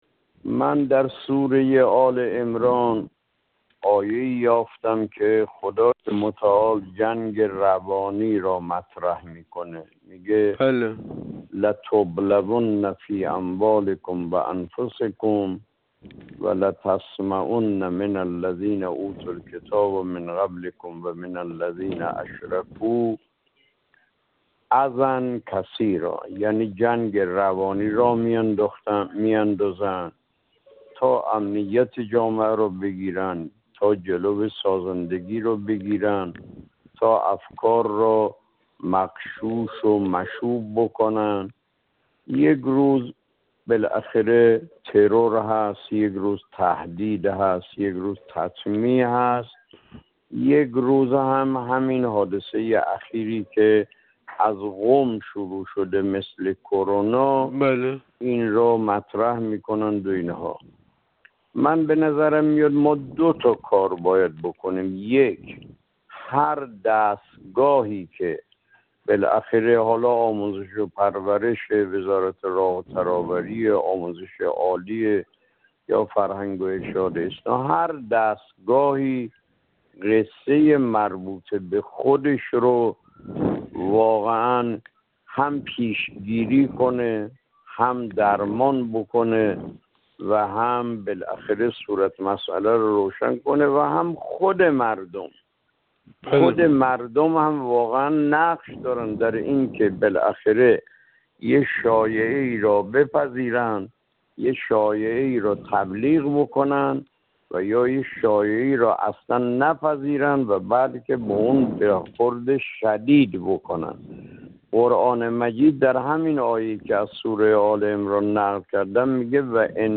حجت‌الاسلام و المسلمین سیدرضا اکرمی، عضو جامعه روحانیت مبارز، در گفت وگو با ایکنا، درباره مسمومیت‌های سریالی اخیر دختران دانش‌آموز در کشور و برخی انتقادات نسبت تأثیر به دیر وارد عمل شدن نهادهای مربوطه بر فراهم کردن زمینه شایعه‌سازی رسانه‌های معاند گفت: خداوند در آیه 186 سوره آل عمران بحث «جنگ روانی» را مطرح می‌کند؛ «لَتُبْلَوُنَّ فِي أَمْوَالِكُمْ وَأَنْفُسِكُمْ وَلَتَسْمَعُنَّ مِنَ الَّذِينَ أُوتُوا الْكِتَابَ مِنْ قَبْلِكُمْ وَمِنَ الَّذِينَ أَشْرَكُوا أَذًى كَثِيرًا ۚ وَإِنْ تَصْبِرُوا وَتَتَّقُوا فَإِنَّ ذَٰلِكَ مِنْ عَزْمِ الْأُمُورِ» (قطعاً در مال‌ها و جان‌هايتان آزموده خواهيد شد، و از كسانى كه پيش از شما به آنان كتاب داده شده و نيز از كسانى كه به شرک گراييده‌اند، سخنان دل‌‌آزار بسيارى خواهيد شنيد، واگر صبر كنيد و پرهيزگارى نماييد، اين ايستادگى حاكى از عزم استوار شما در كارهاست.)